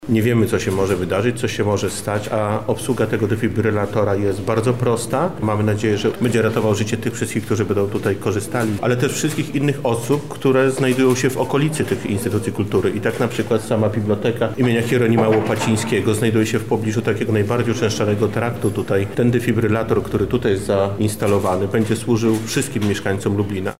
O instalacji i przeznaczeniu tych urządzeń mówi Bartłomiej Bałaban, Członek Zarządu Województwa Lubelskiego: